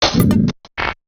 UI_SFX_Pack_61_2.wav